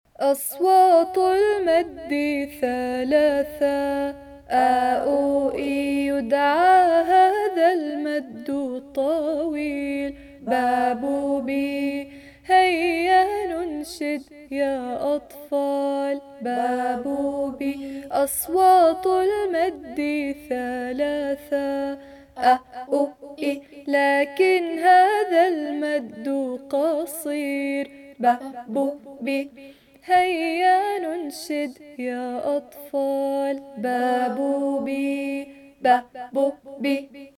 انشد حروف المد والحركات صف اول فصل اول منهاج اردني